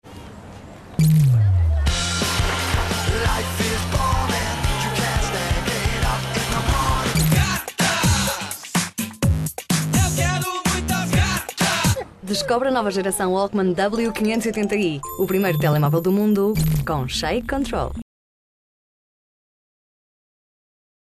Portuguese flexible female voice with different registers, from a woman, young mother's to a child's. Voice over; Narration; Institutional videos; Documentary; Publicity.
Sprechprobe: Industrie (Muttersprache):
Professional Portuguese VO talent.